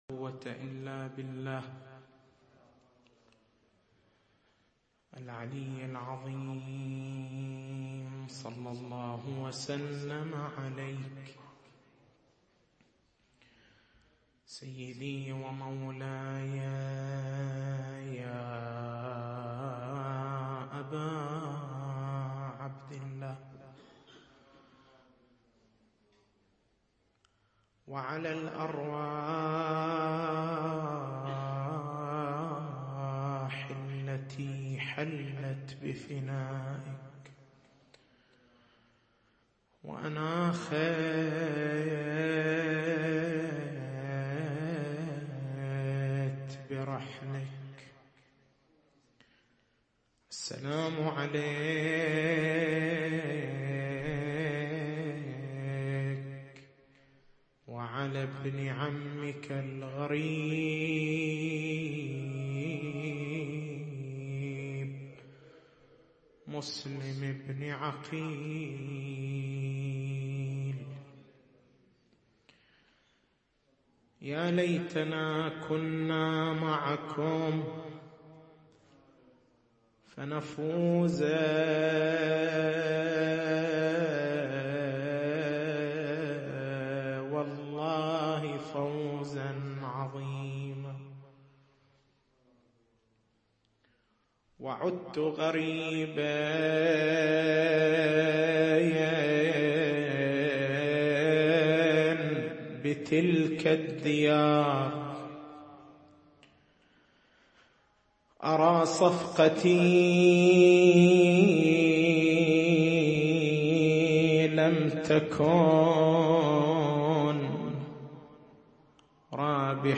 تاريخ المحاضرة: 18/01/1434 نقاط البحث: ما هو المقصود من عنوان الأمر؟